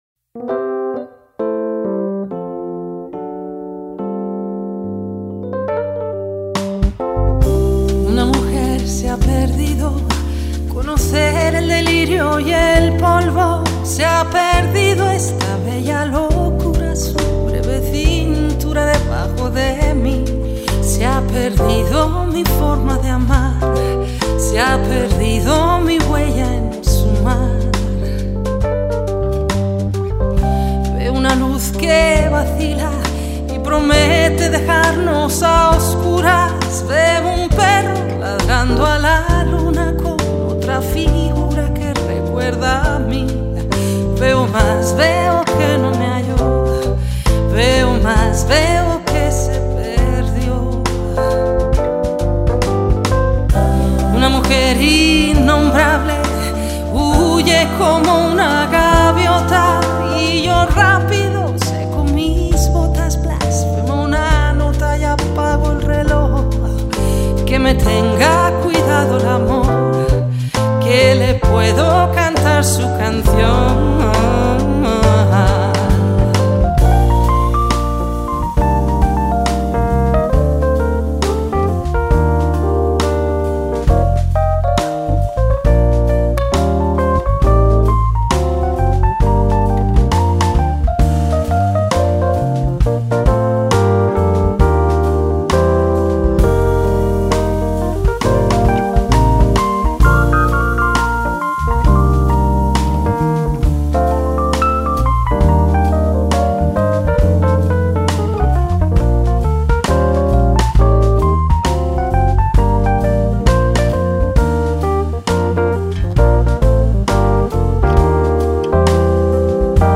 Жанр: Alternativa.